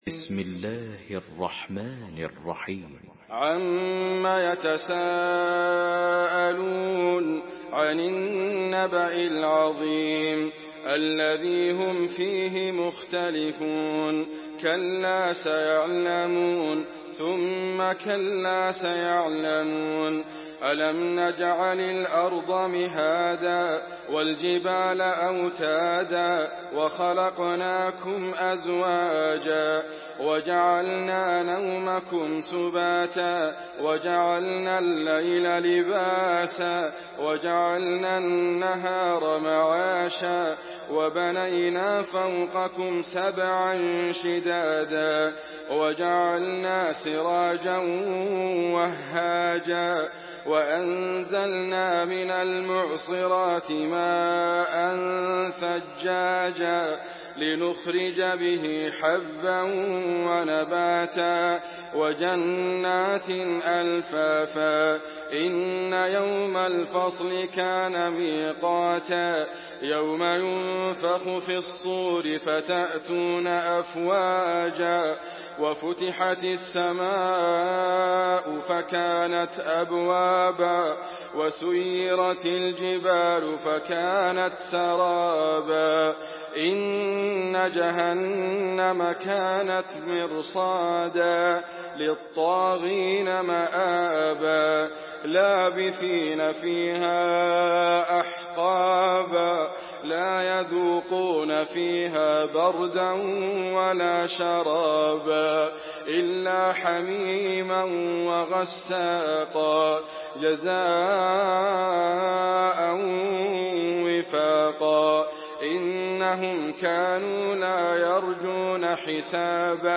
سورة النبأ MP3 بصوت إدريس أبكر برواية حفص
مرتل حفص عن عاصم